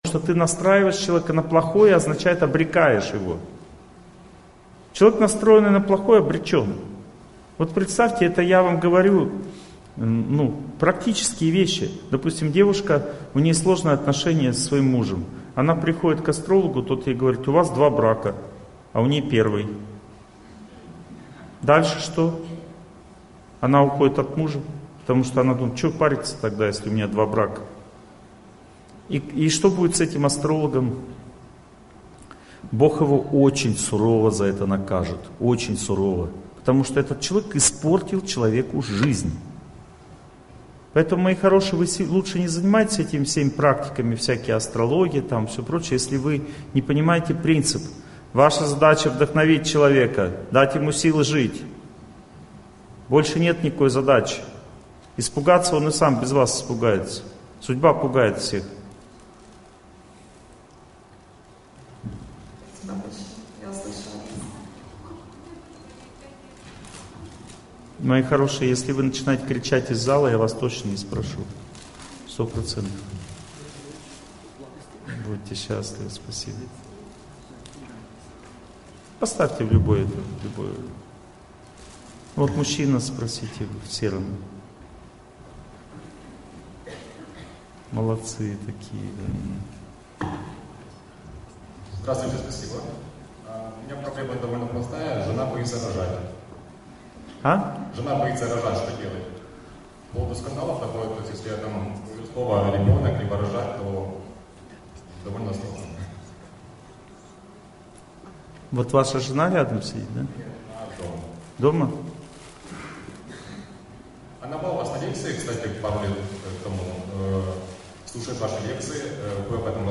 Vliyanie-na-sudbu-otnosheniy-s-drugimi-lyudmi-Lekciya-3.mp3